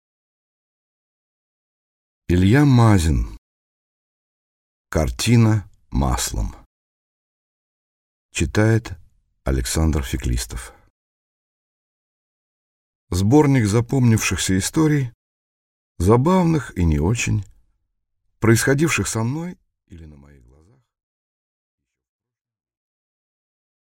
Aудиокнига Картина маслом Автор Илья Мазин Читает аудиокнигу Александр Феклистов.